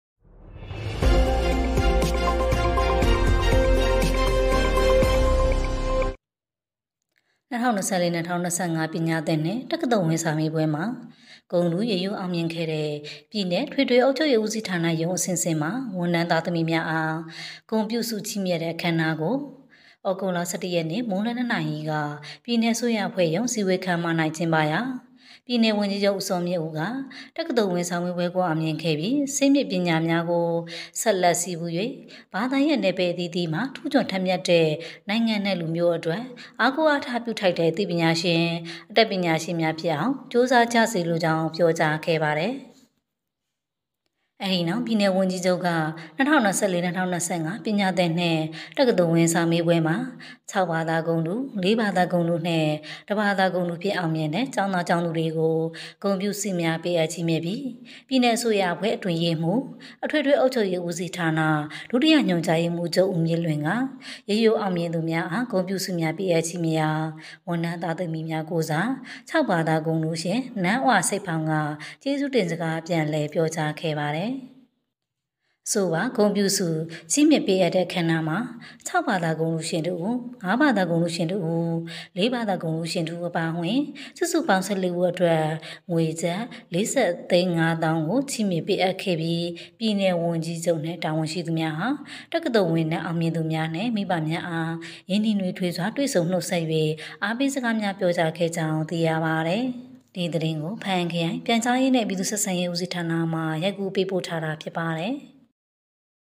တက္ကသိုလ်ဝင်စာမေးပွဲတွင် (ဂုဏ်ထူး/ရိုးရိုး)အောင်မြင်ခဲ့သော ပြည်နယ်အထွေထွေအုပ်ချုပ်ရေးဦးစီးဌာနရုံးအဆင့်ဆင့်မှ ဝန်ထမ်းသား၊သမီးများအား ဂုဏ်ပြုဆု ချီးမြှင့်သည့်အခမ်းအနားကျင်းပ